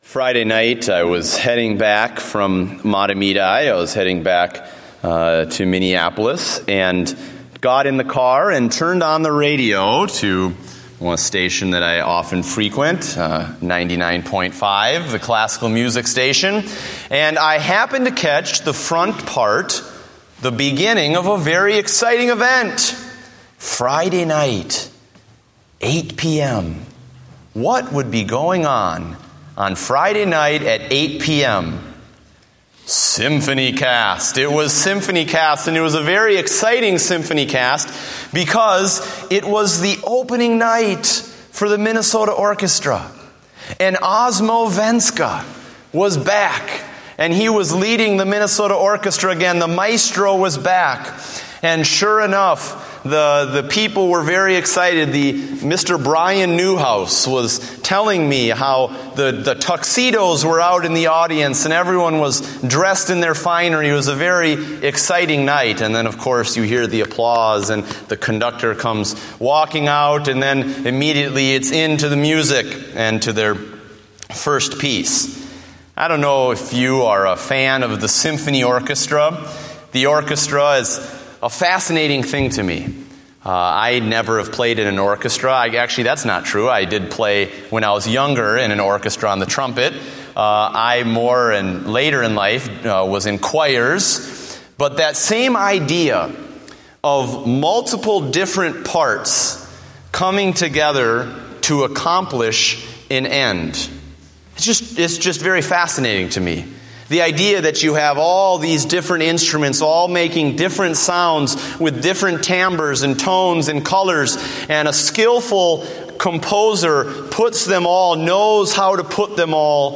Date: September 7, 2014 (Evening Service)